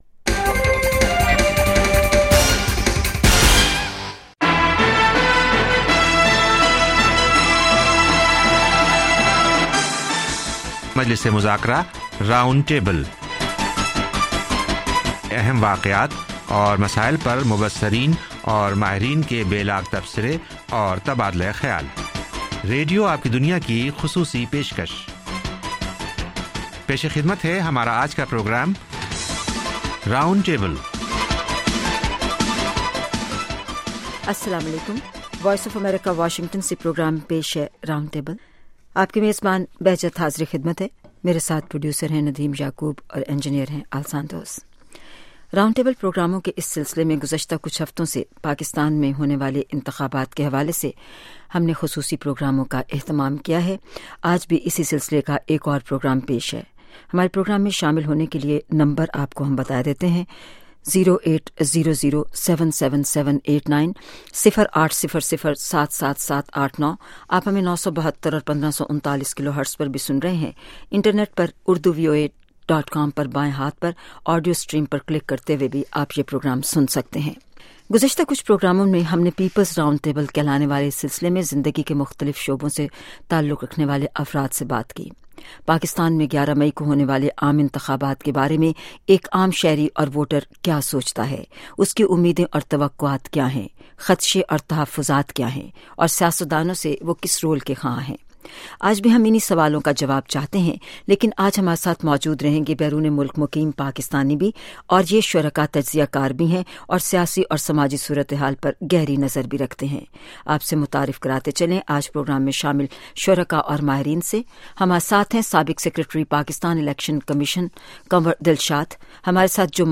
Topic: Peoples Roundtable; Election Concerns & Expectations of common people (Special Election Programs)